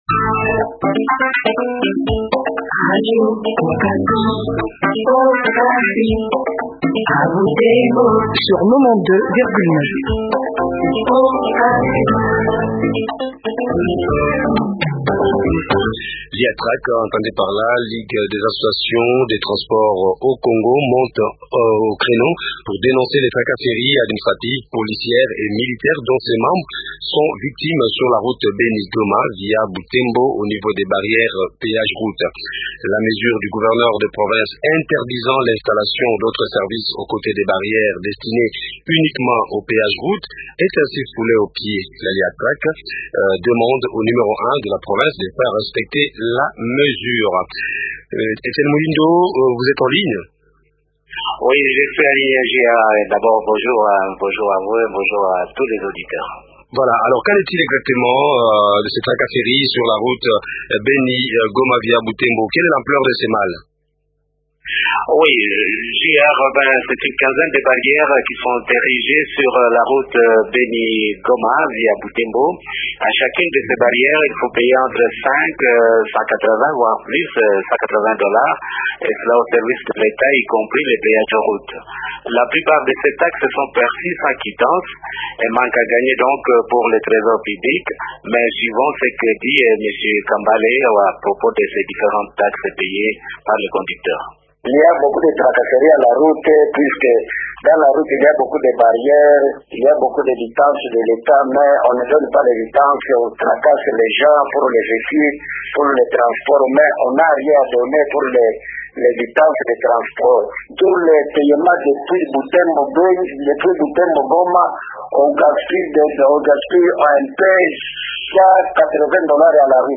recoivent Julien Paluku, gouverneur du Nord-Kivu